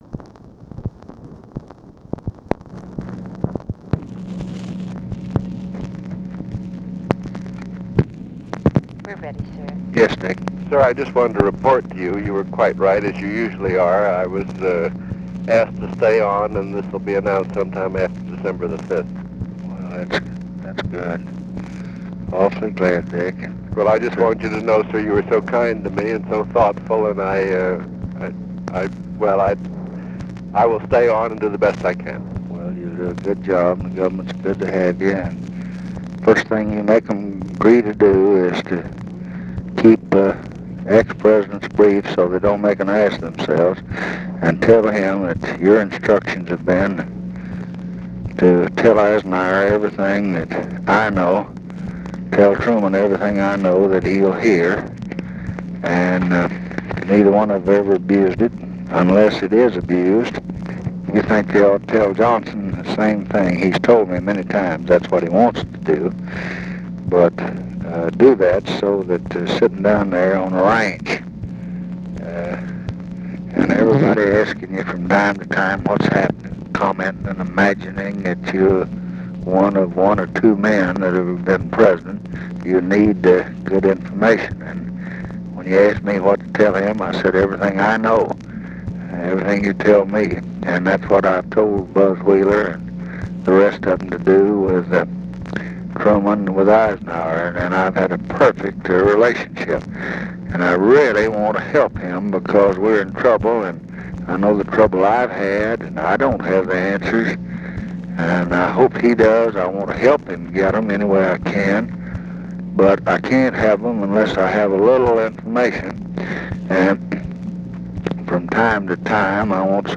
Conversation with RICHARD HELMS, November 16, 1968
Secret White House Tapes